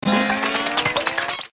TL_train_track_appear.ogg